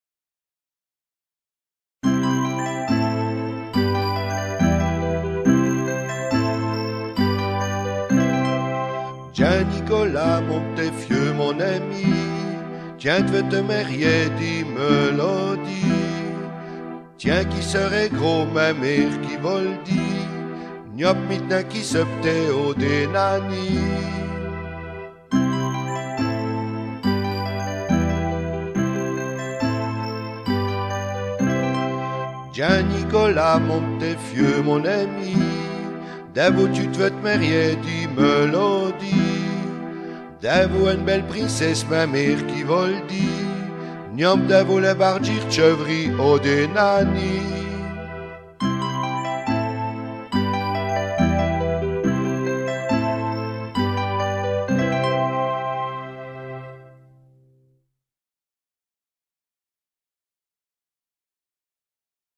Bréçouse, berceuse
Breçouse, paroles et musique Breçouse, paroles et musique Breçouse, musique Breçouse, musique {{Partition et textes en français et patois}} Bréçouse 1 Bréçouse 2